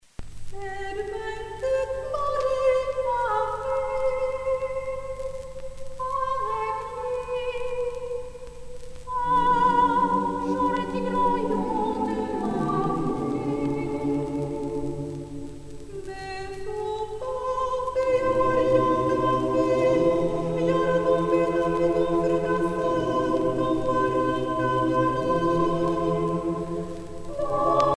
Genre dialogue Artiste de l'album Franco-Allemande de Paris (chorale)
Pièce musicale éditée